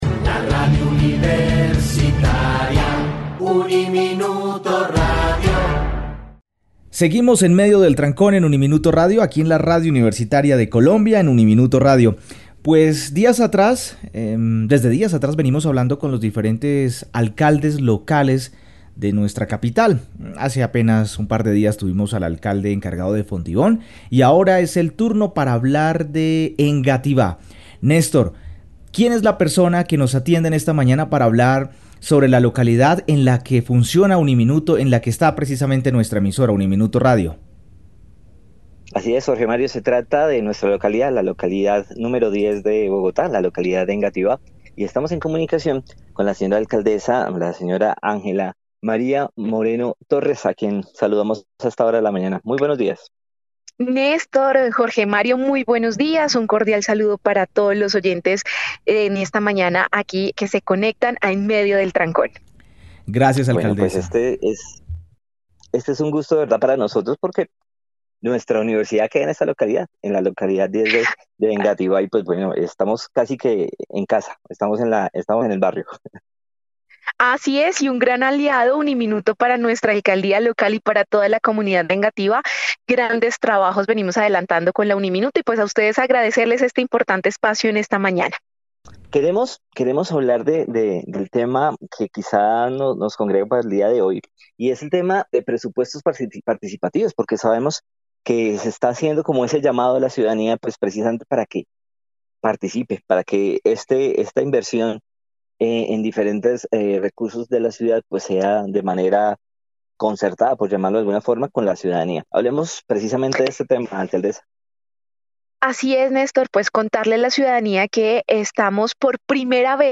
Angela María Moreno Torres, alcaldesa local de Engativá, conversó con UNIMINUTO Radio a propósito de cómo los habitantes de cada localidad pueden decidir en qué se debe invertir el presupuesto.